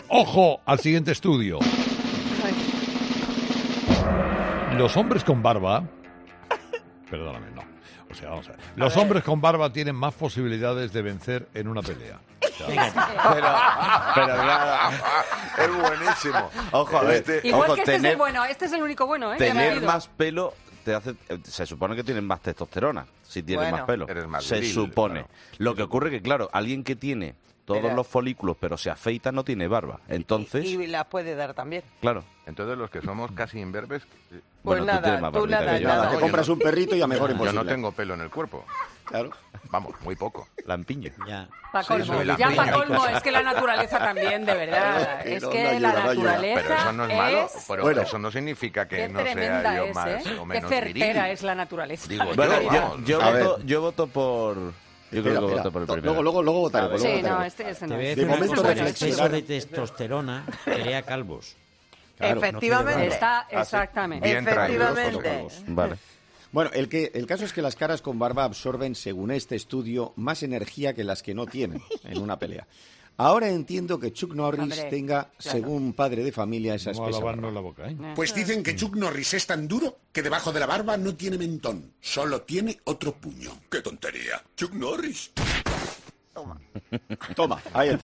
"Buenísimo", reaccionan todos los presentes entre risas.